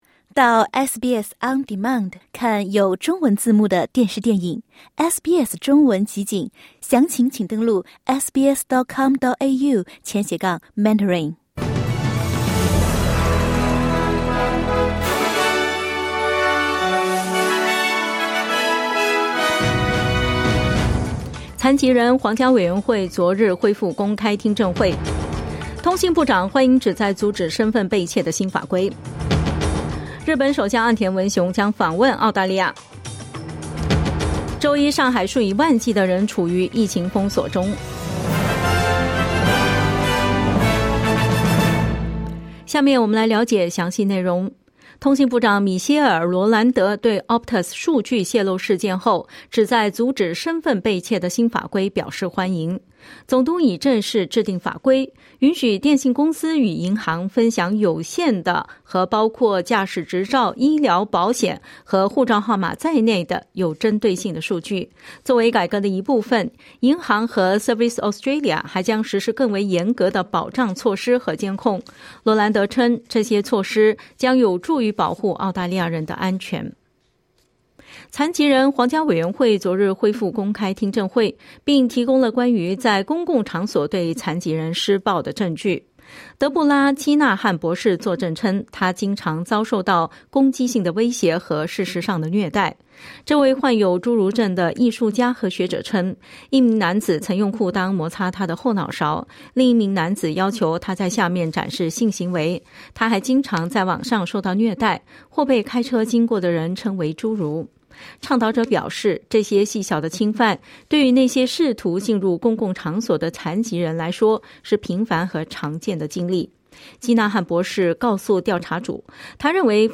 SBS早新闻（10月11日）
请点击收听SBS普通话为您带来的最新新闻内容。